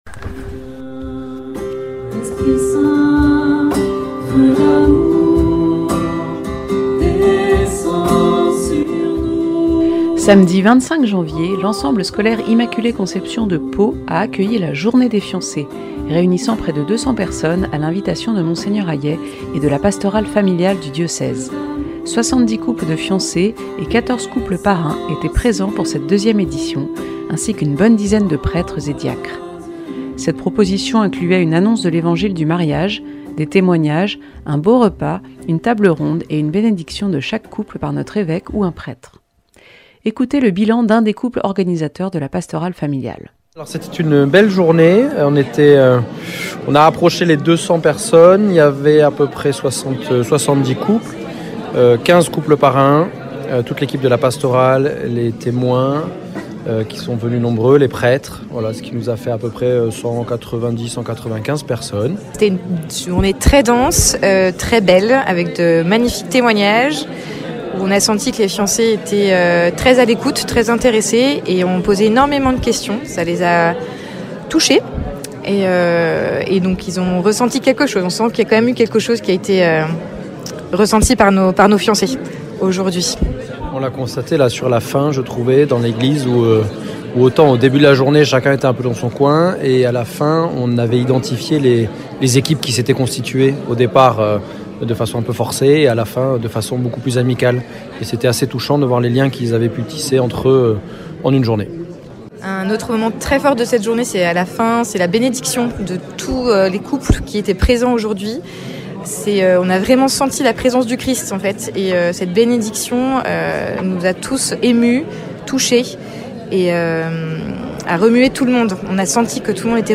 Journée des fiancés le 25 janvier 2025 à Pau. Reportage.